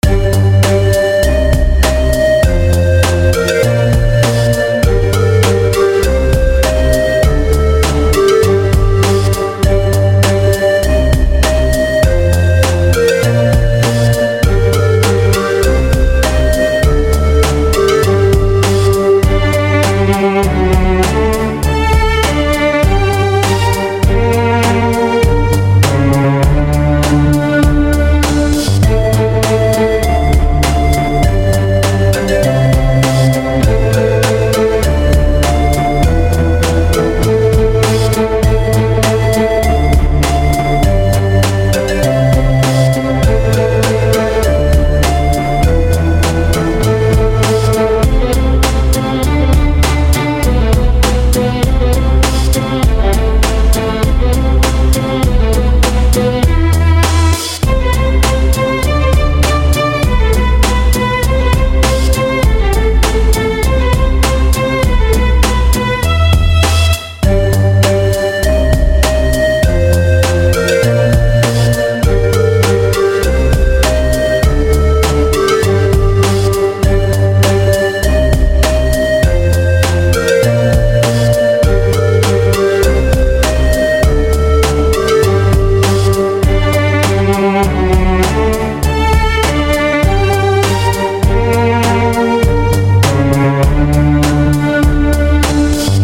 • Качество: 149, Stereo
скрипка